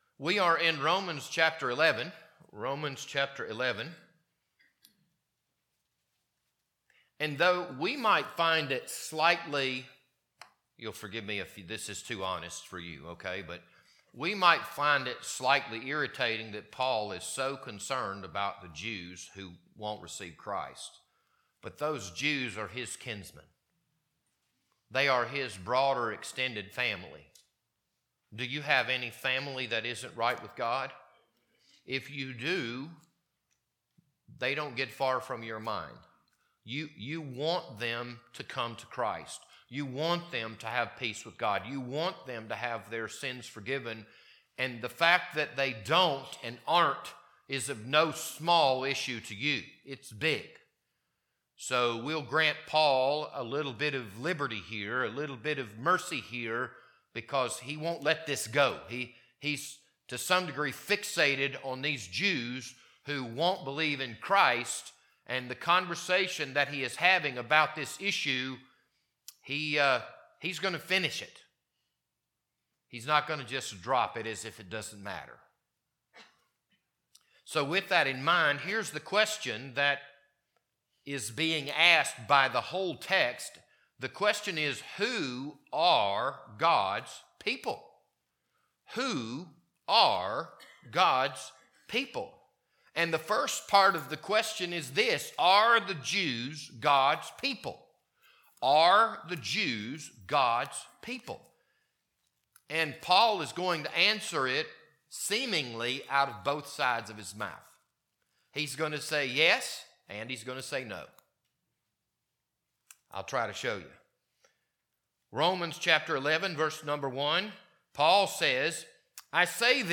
This Sunday morning sermon was recorded on February 2nd, 2025.